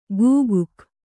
♪ gūguk